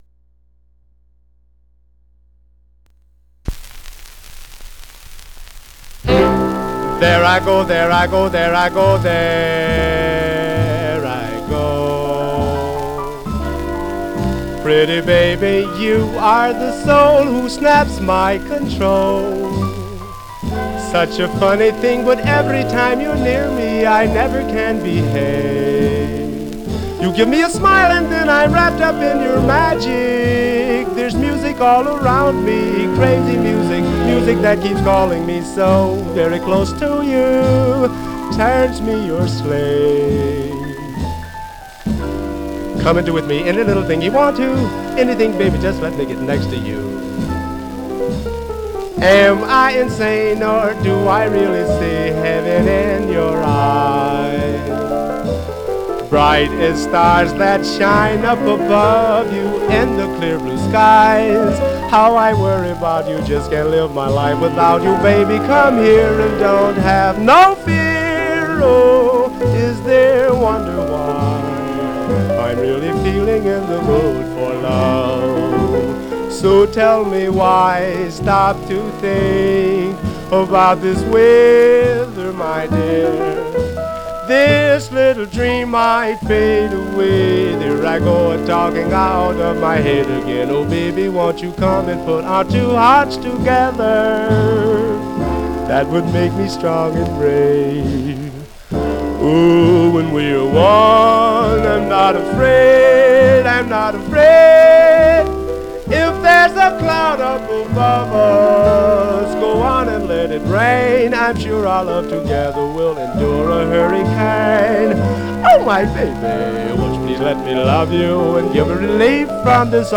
Some surface noise/wear Stereo/mono Mono
Jazz